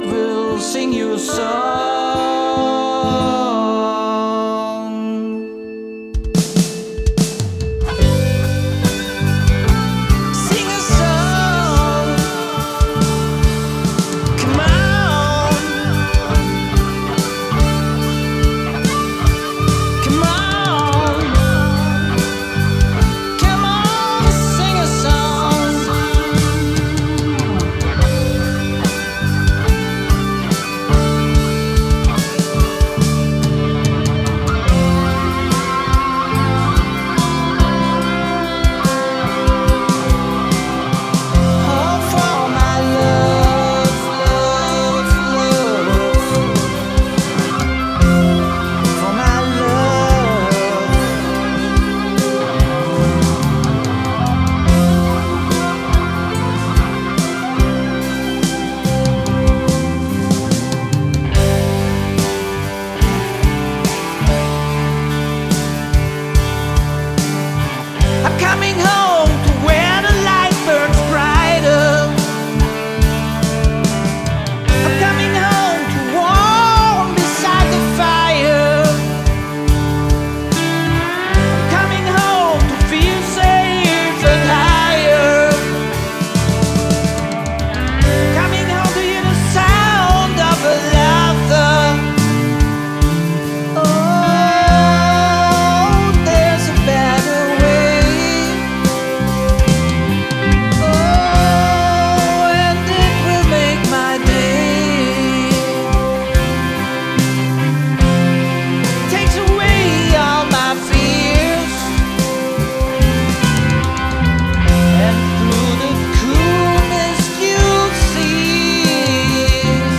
70er Ballade
So, hier dann mal eine erste Fassung (nur der "laute" Teil des Songs) mit echtem Bass. Wie immer spiele ich auf dem Bass nur relativ wenige Töne ohne viel Gefrickel - wenn das anders sein soll, dann sollte lieber jemand anders den Bass bedienen.